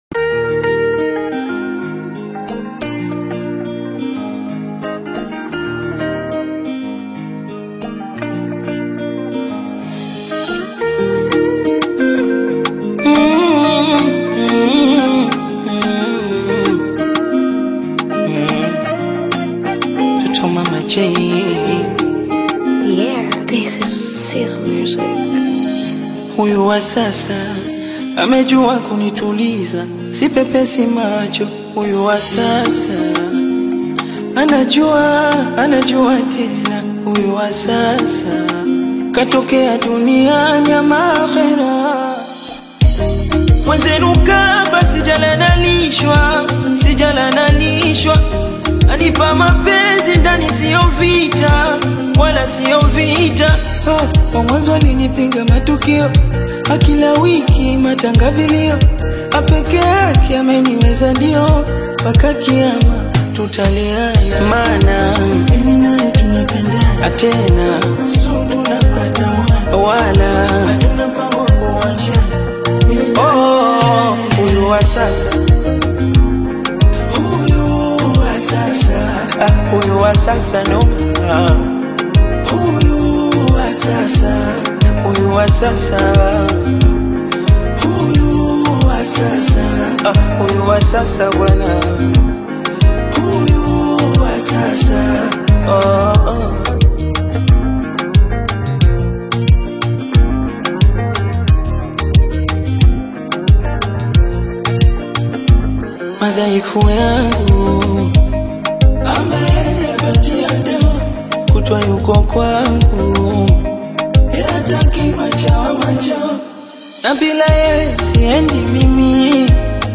AudioBongo flava
energetic Afro‑Pop/Bongo Flava single
With infectious hooks and smooth beats